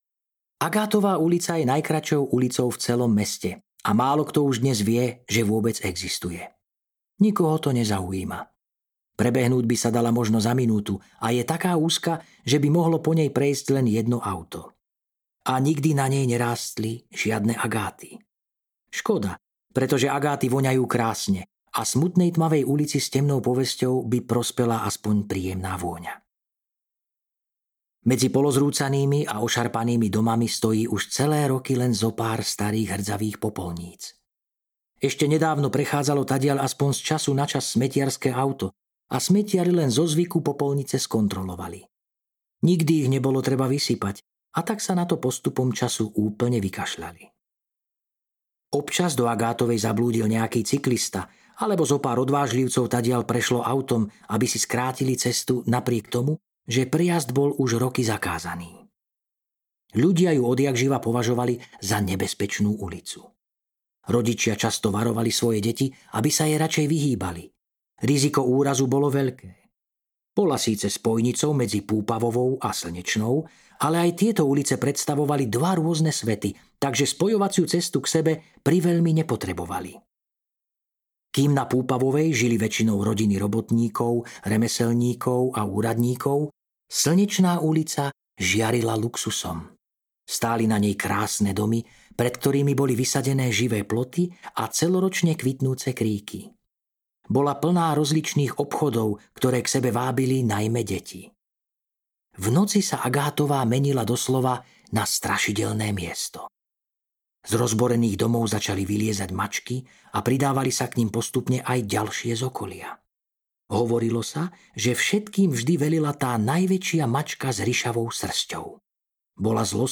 Stratené topánky audiokniha
Ukázka z knihy